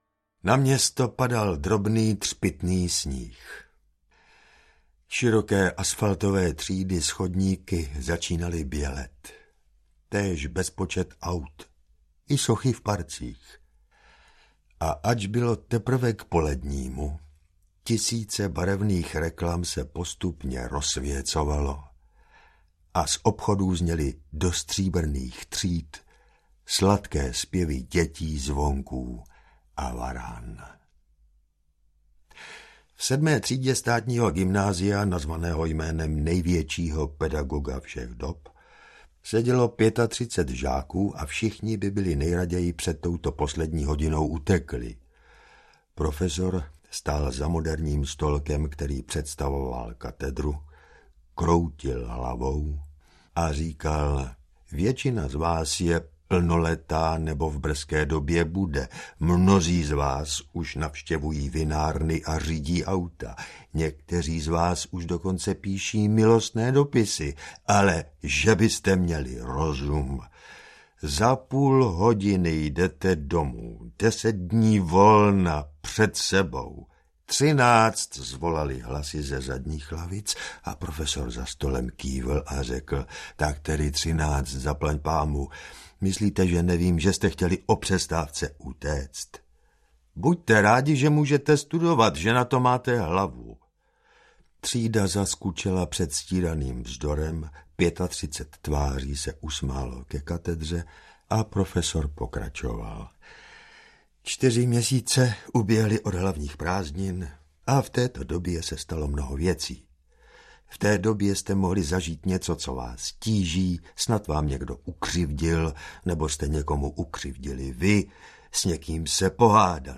Příběh kriminálního rady audiokniha
Ukázka z knihy
• InterpretJan Hartl